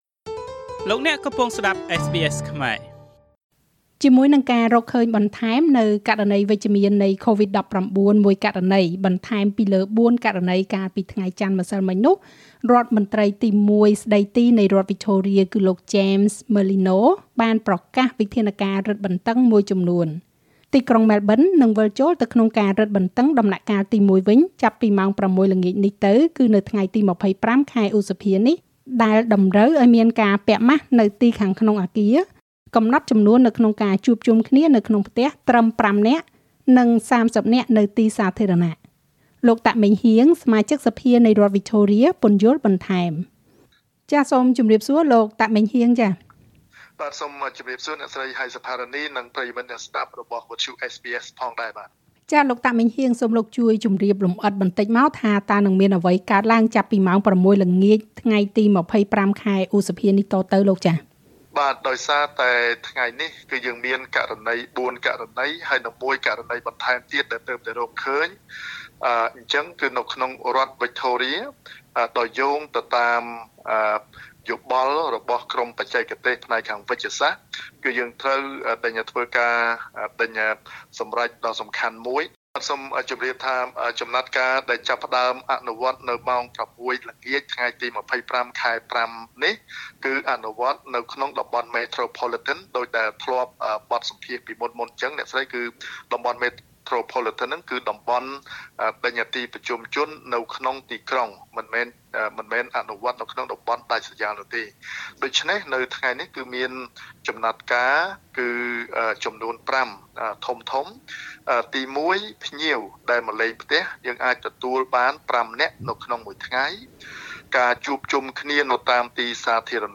លោក តាក ម៉េងហ៊ាង សមាជិកសភានៃរដ្ឋវិចថូរៀ ពន្យល់បន្ថែម។